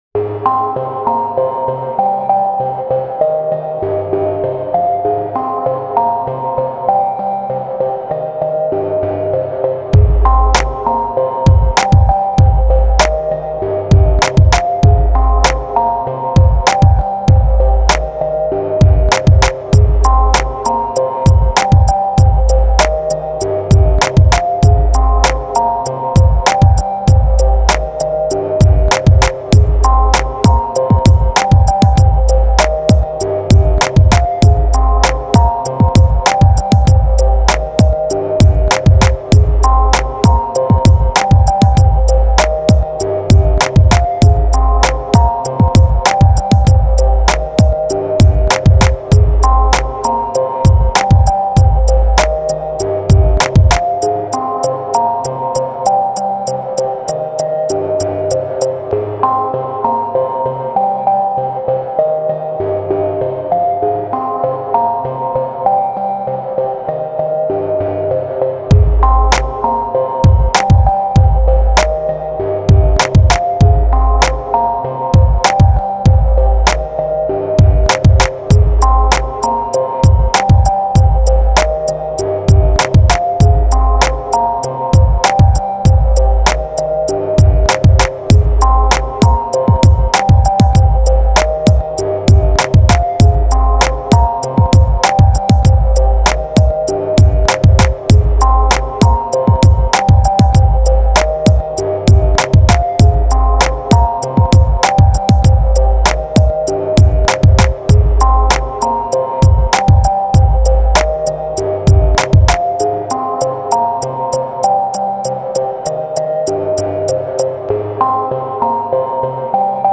ORIGINAL INSTRUMENTALS